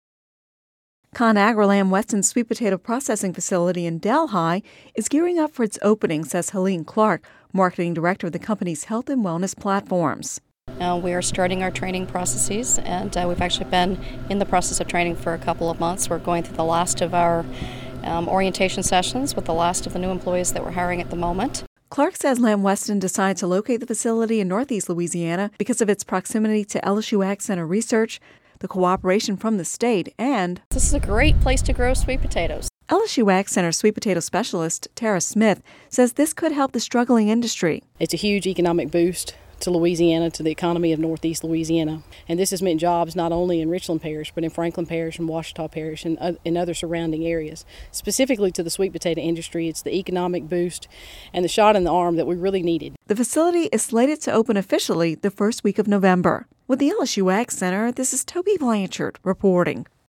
Radio News 09/20/10